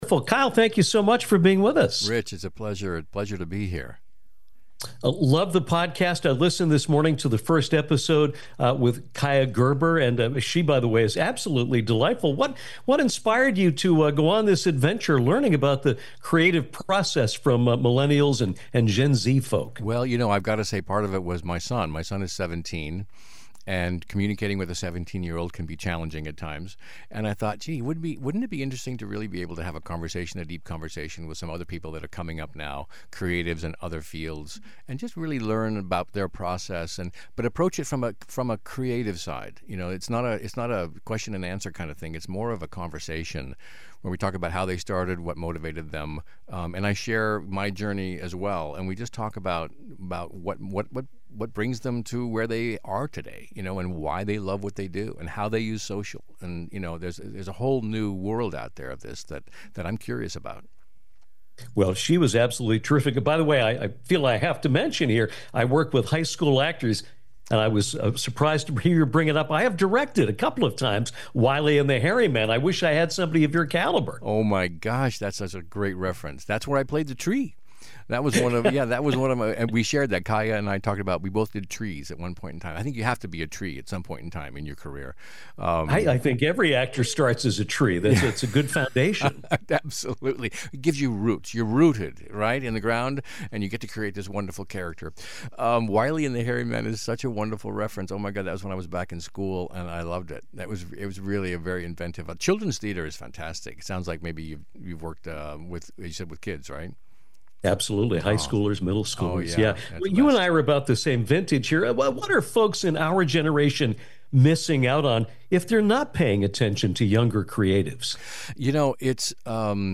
Actor Kyle MacLachlan joined us to discuss his new podcast, WHAT ARE WE EVEN DOING, available on iHeart Radio, as well as his remarkable collaboration with director David Lynch.